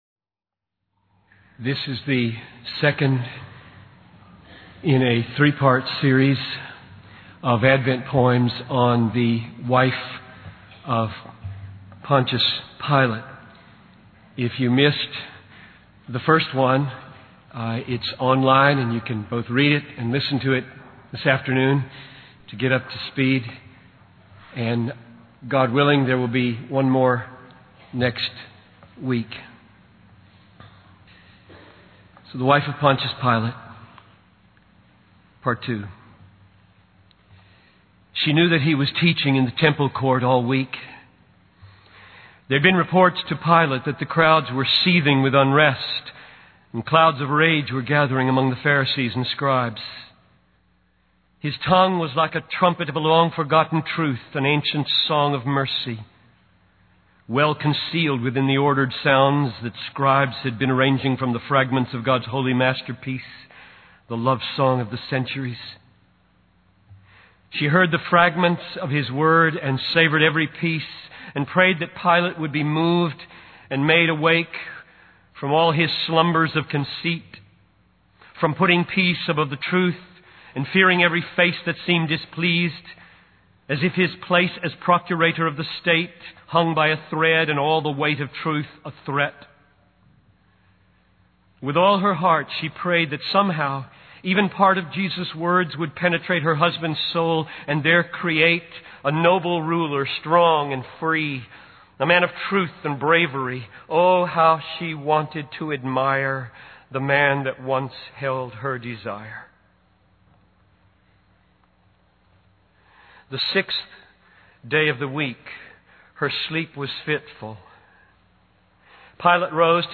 In this sermon, the preacher vividly describes the scene of Jesus' crucifixion and the emotional turmoil experienced by those who witnessed it. The preacher emphasizes the innocence of Jesus and the injustice of his execution, highlighting the pain and suffering he endured.